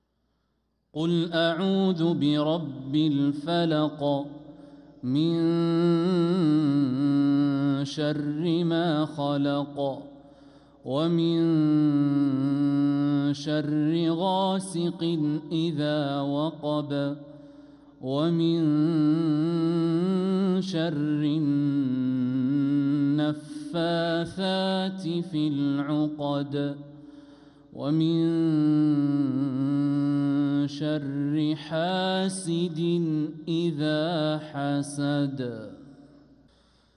سورة الفلق | فروض جمادى الآخرة 1446هـ